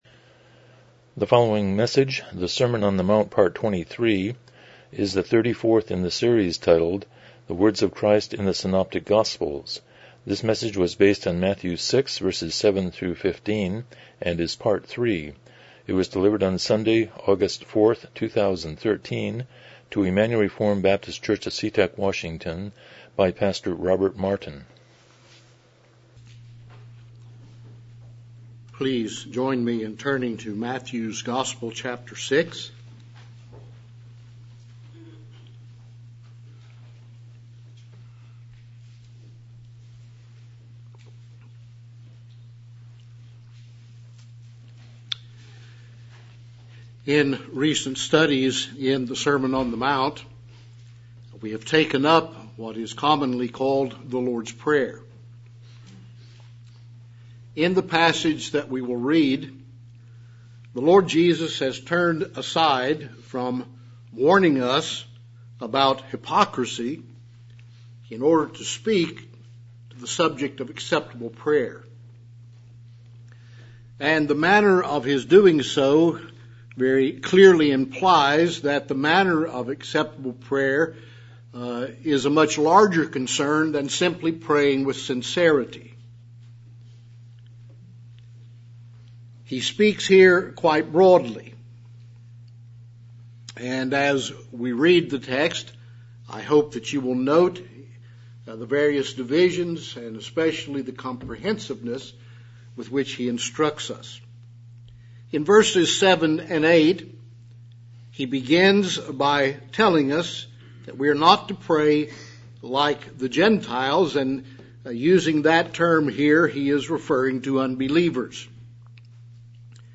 Passage: Matthew 6:7-15 Service Type: Morning Worship